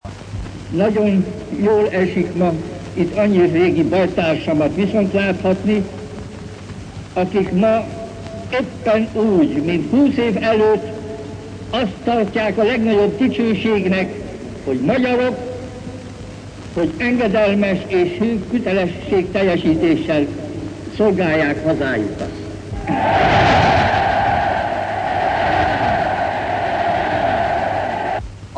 Horthy a Szt. György téren összegyûlt, 20 éves országlását ünneplõ tömeg, elõtt beszél az Országos Nemzetvédelmi Bizottság által összehívott gyûlésen.